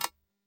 Camera Shutter
A crisp DSLR camera shutter click with mirror slap and film advance sound
camera-shutter.mp3